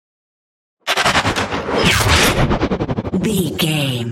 Sci fi gun shot hit whoosh
Sound Effects
heavy
intense
dark
aggressive